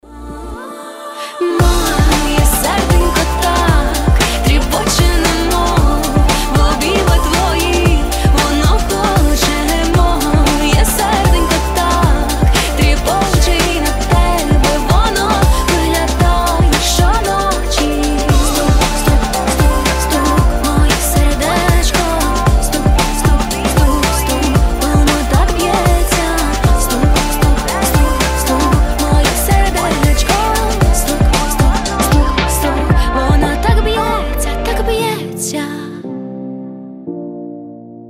Танцевальные рингтоны, Рингтоны на Любимую